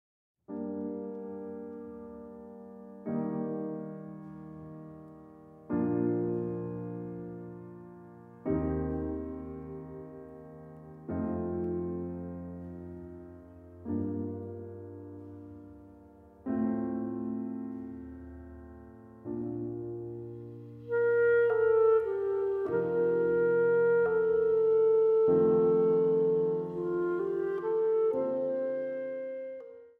Instrumentaal | Klarinet
Instrumentaal | Piano